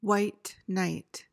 PRONUNCIATION: (HWYT nyt) MEANING: noun: 1.